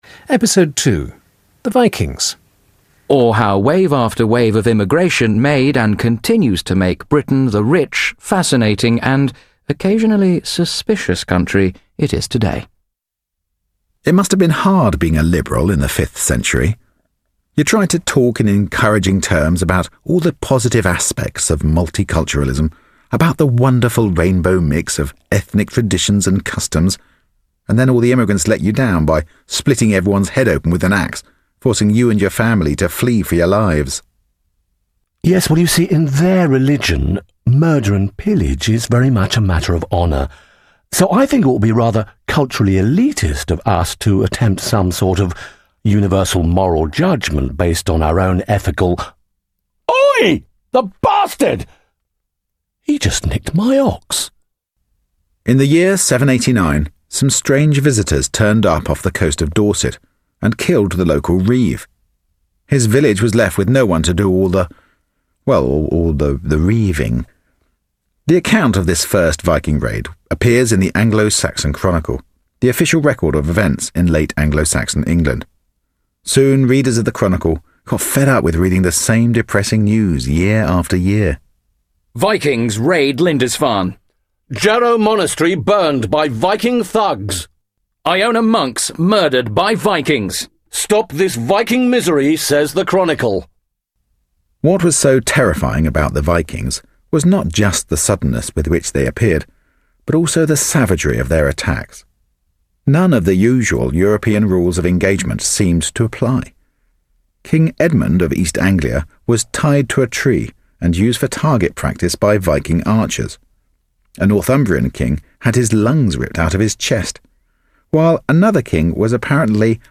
Hij heeft trouwens ook al gedeelten eruit als hoorspel gebracht, op de BBC radio.